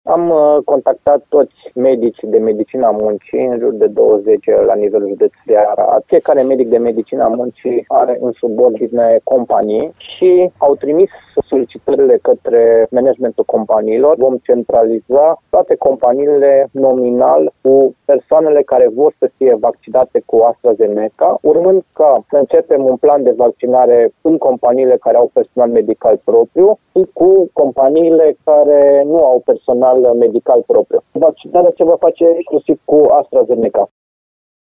Direcția de Sănătate Publică Arad așteaptă să primească, săptămâna aceasta, răspunsul companiilor pentru a putea vedea în ce condiții va demara vaccinarea.  Directorul instituției, Horea Timiș,